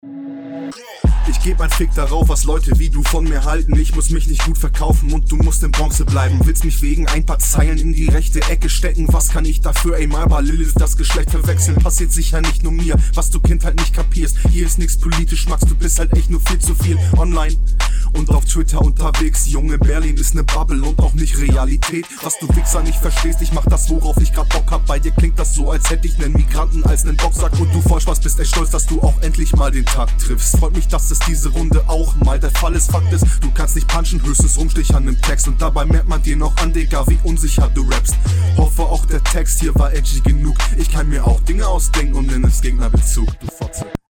Fand Flow hier deutlich besser und flüssiger als beim Gegner.